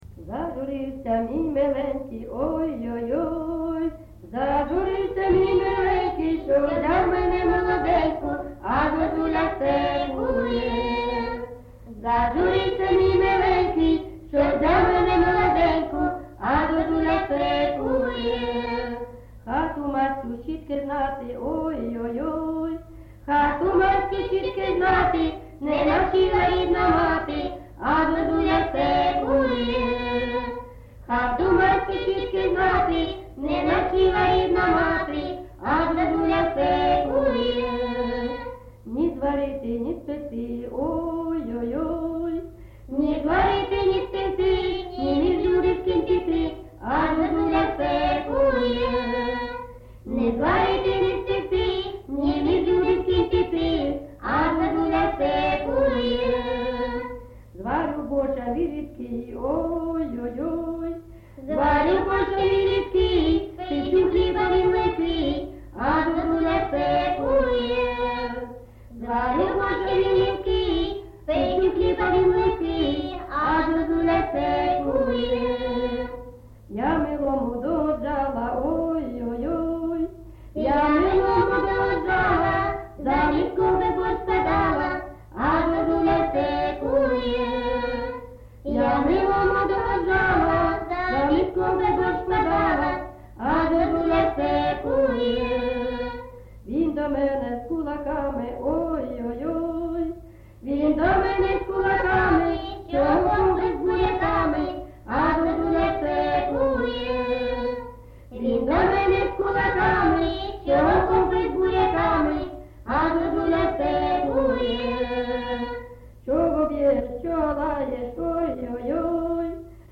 ЖанрПісні з особистого та родинного життя, Сучасні пісні та новотвори
Місце записус. Золотарівка, Сіверськодонецький район, Луганська обл., Україна, Слобожанщина